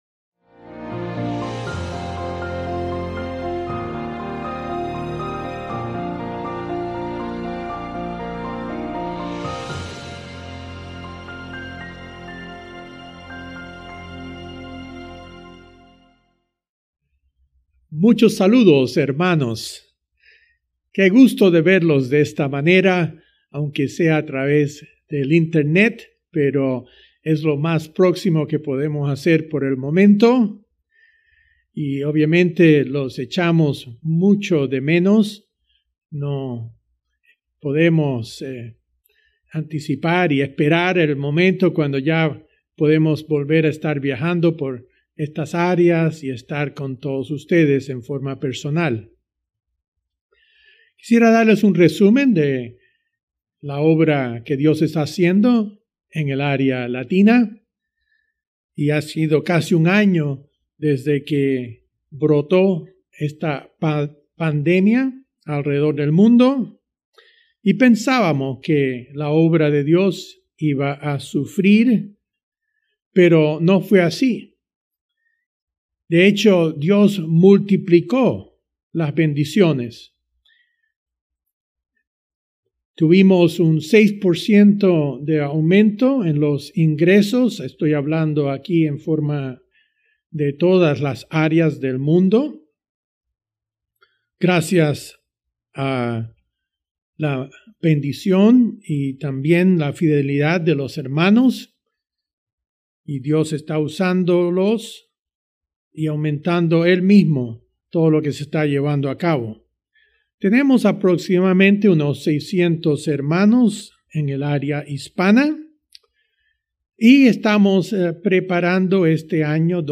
Un aspecto a considerar cuando se dice uno cristiano, es que uno adquiere un compromiso de por vida. Mensaje entregado el 23 de enero de 2021.